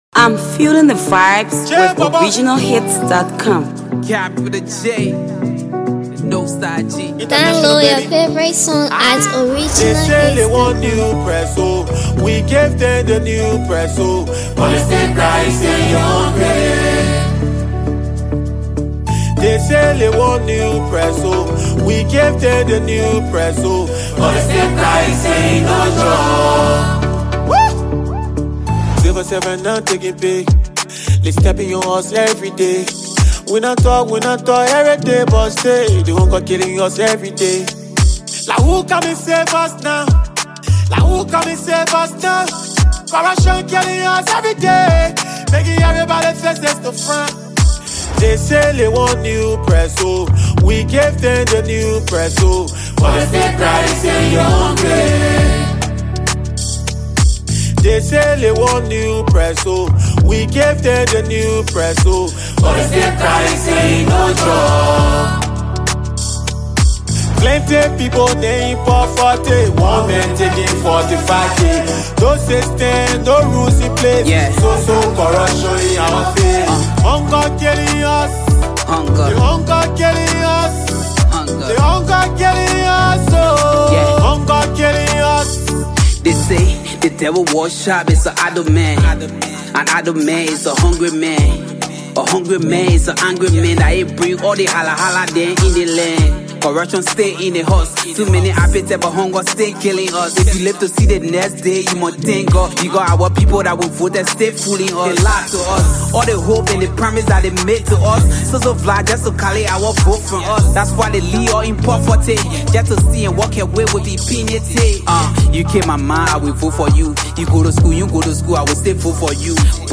” featuring sensational Liberian rapper
Liberian award-winning Gbema artist